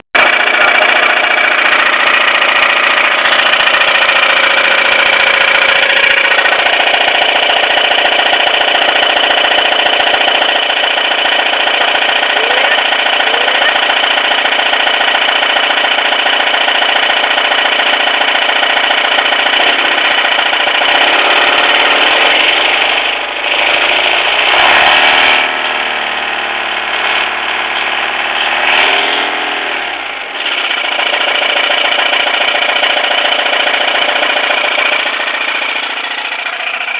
Tema: Re: b5 1.9 tdi variklio garsas, reikia ausu
Pravaziuota ~200km ir pastebetas variklio kalimas ant
> laisvu.
> >2000rpm, garsas pasidaro normalus.